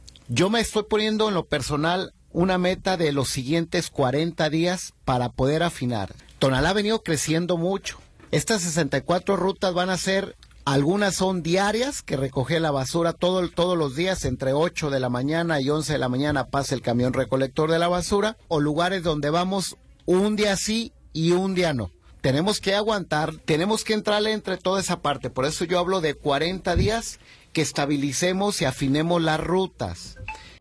alcalde.m4a